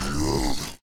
spawners_mobs_death2.ogg